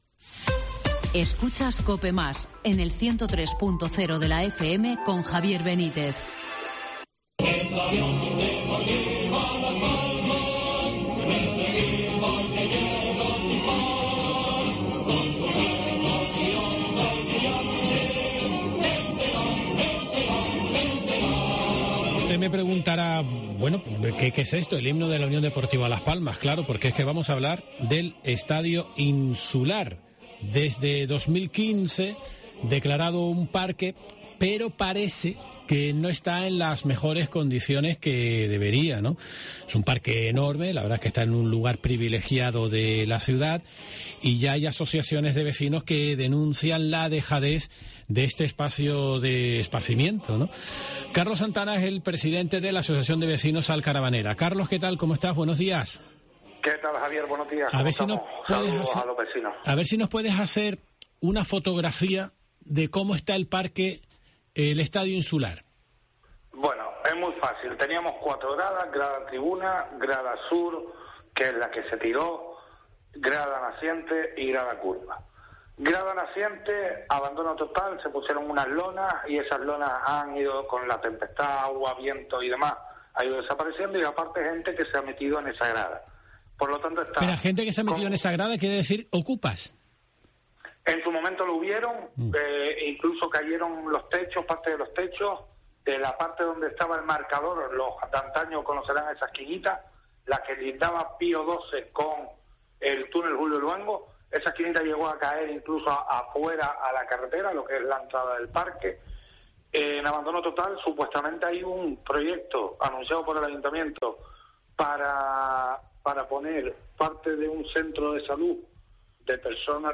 Herrera en COPE Gran Canaria